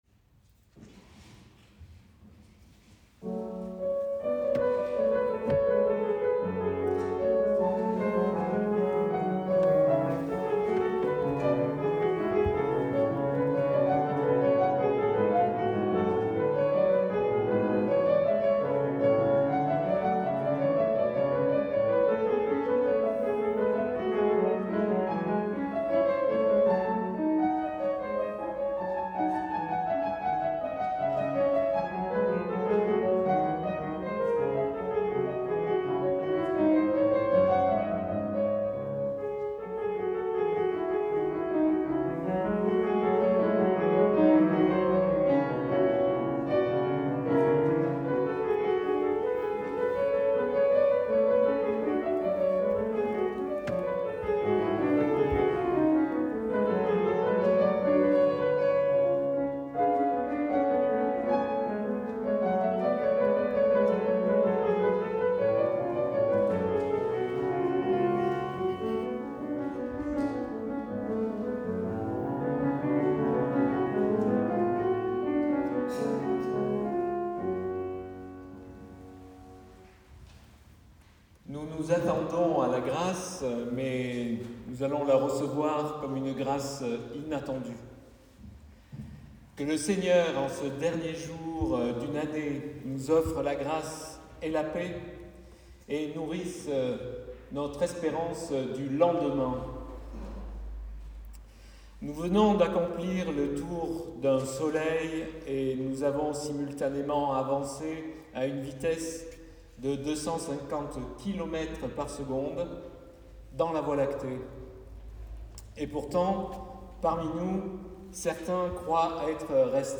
LA CONSOLATION QUI VIENT, CULTE DU 31 DÉCEMBRE 2023